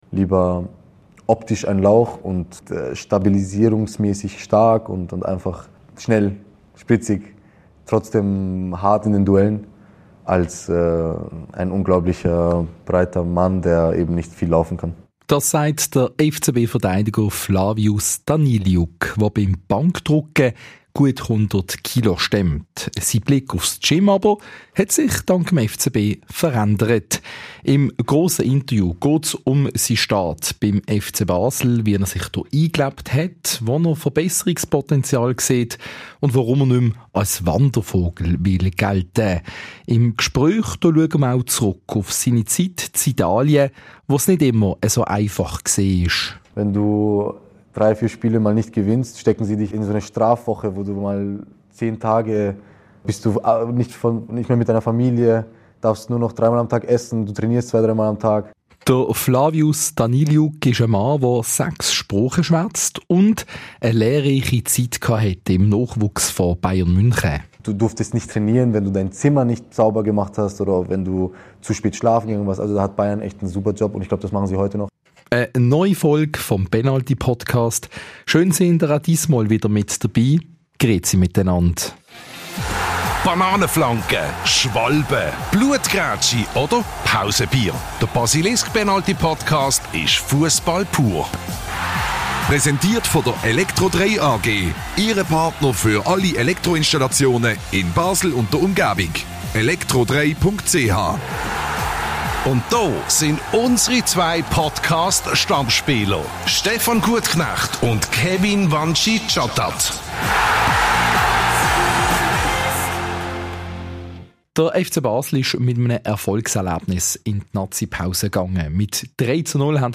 Im Interview spricht Daniliuc über prägende Jahre im Nachwuchs von Real Madrid und Bayern München. In Madrid hat er als 12-Jähriger das Internat besucht und lebte weit weg von seinen Eltern.